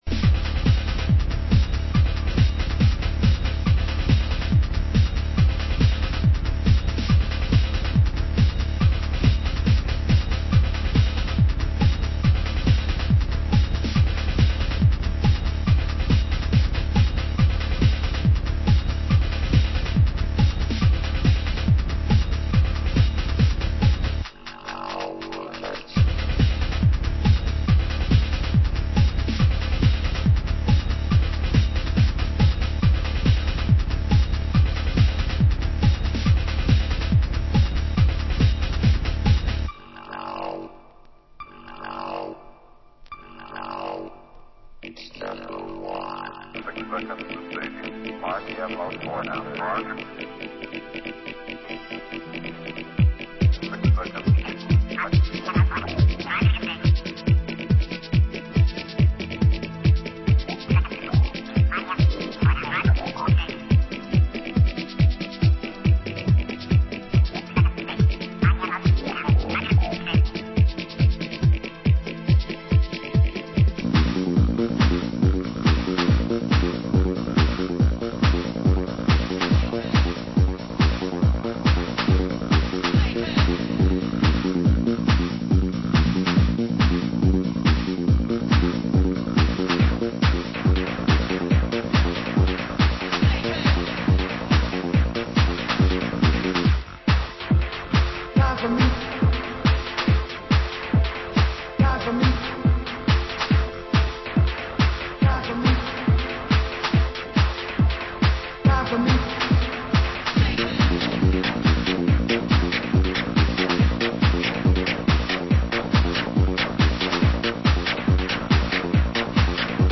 Genre: Euro Techno